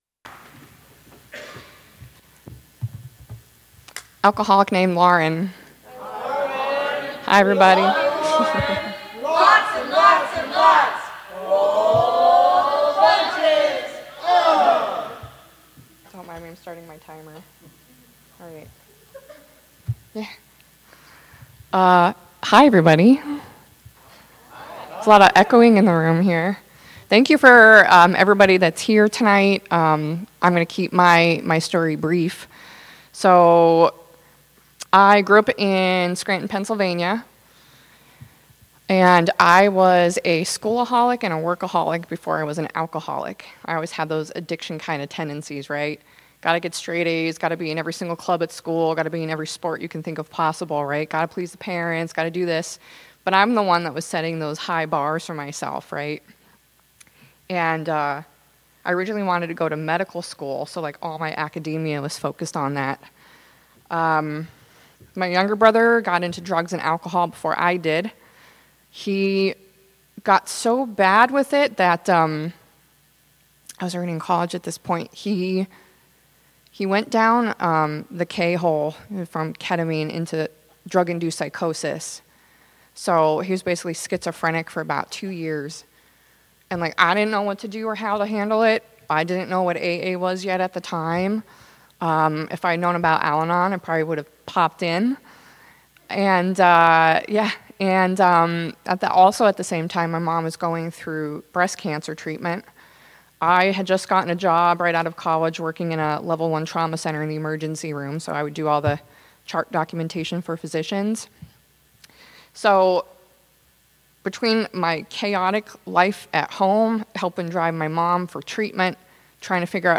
DCYPAA Speakers at 50th MAAD DOG DAZE